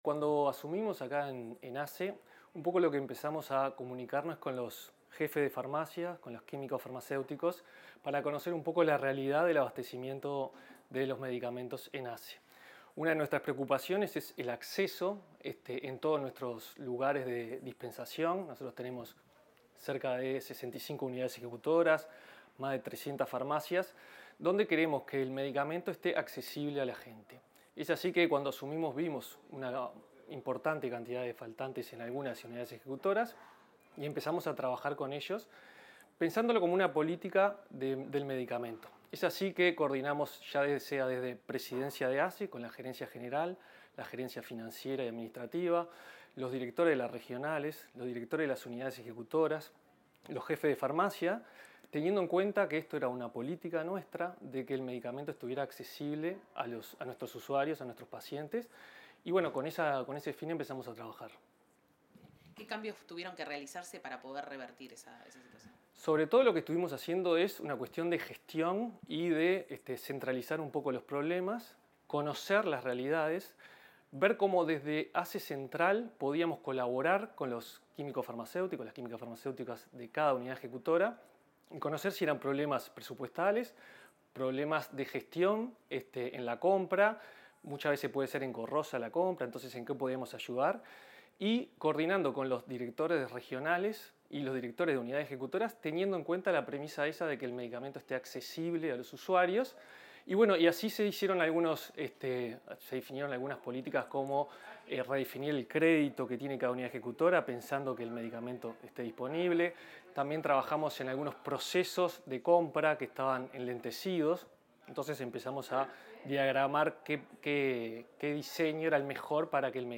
Declaraciones del director de Medicamentos de ASSE, Ismael Olmos